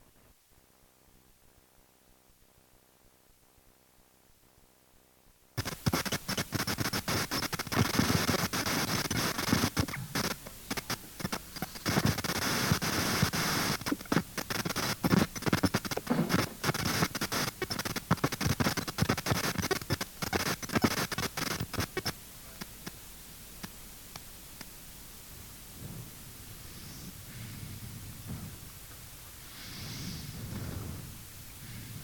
Massive Störgeräusche mit Creative X-Fi Xtreme Gamer im ASUS P6T Board
Stoergeraeusche_xfi.mp3